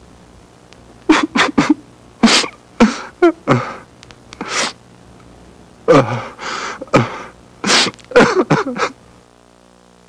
Crying (male) Sound Effect
Download a high-quality crying (male) sound effect.
crying-male.mp3